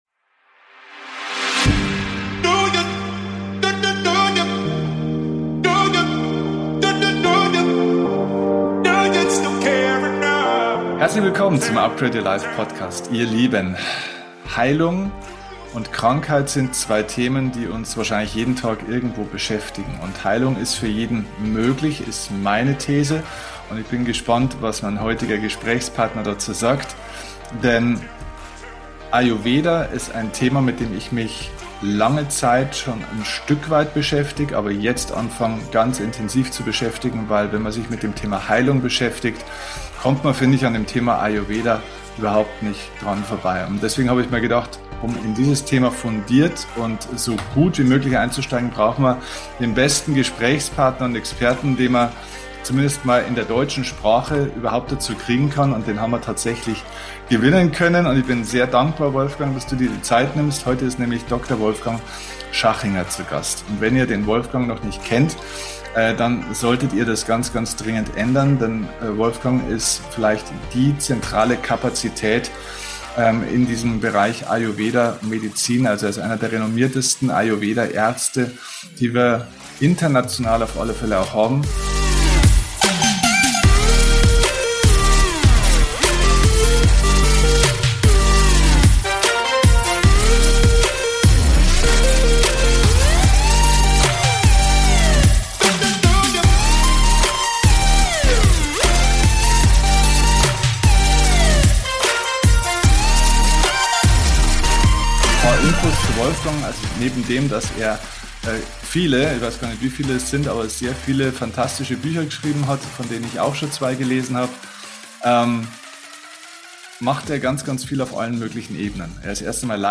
#449 Heilung durch Ayurveda - Interview